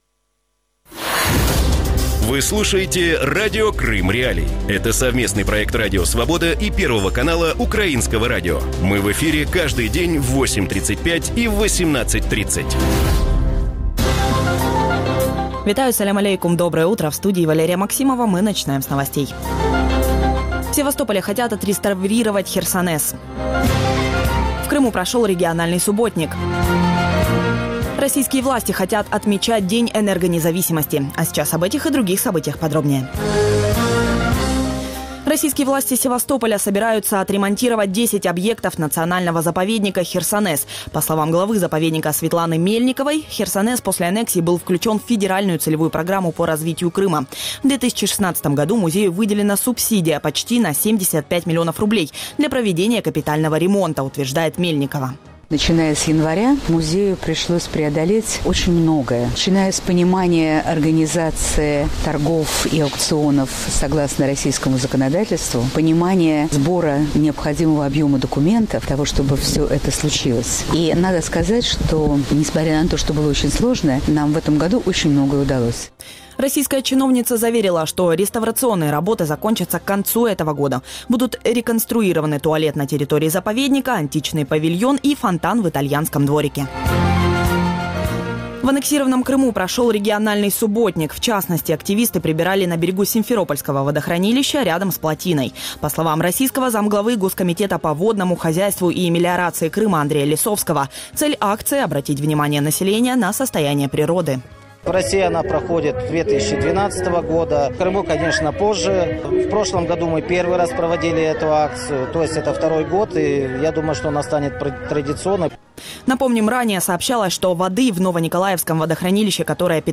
Ранковий ефір новин про події в Криму. Усе найважливіше, що сталося станом на цю годину.